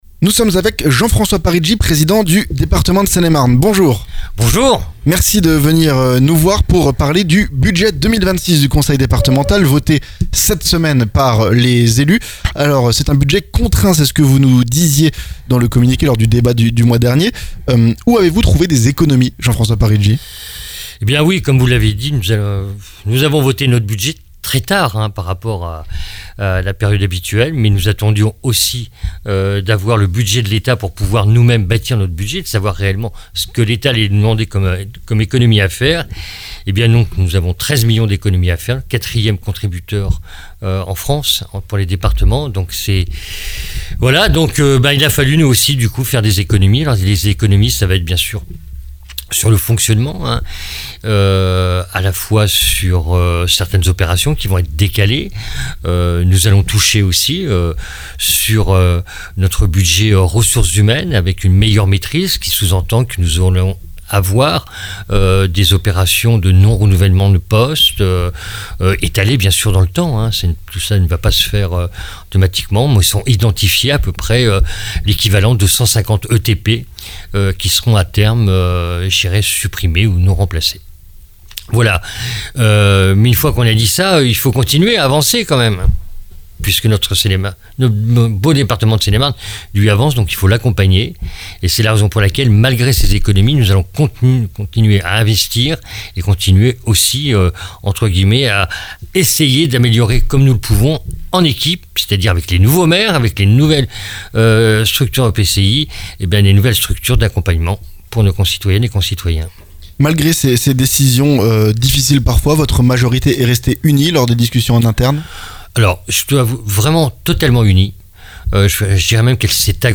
Notre entretien avec Jean-François Parigi, Président (LR) du Conseil départemental de Seine-et-Marne, à l'occasion du vote du budget 2026, marqué par des économies à réaliser.